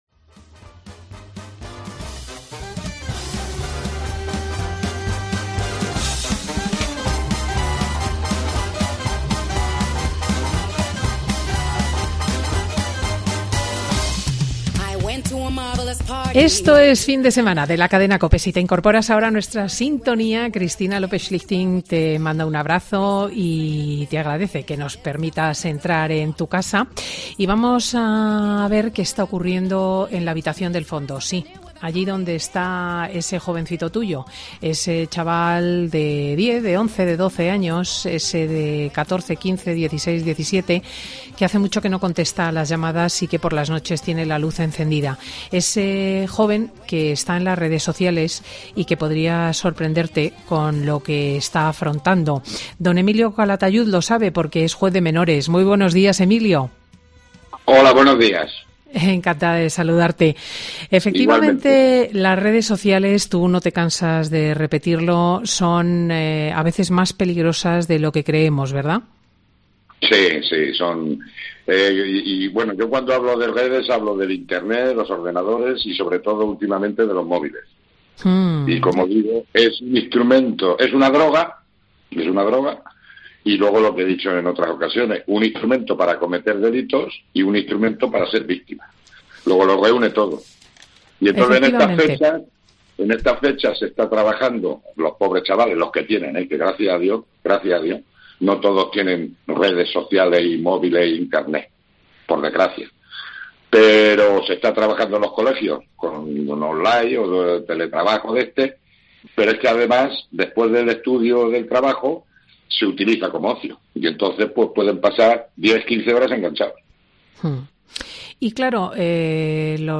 AUDIO: El juez de menores de Granada vuelve a Fin de Semana con Cristina para advertir de los delitos sexuales que se cometen por smartphone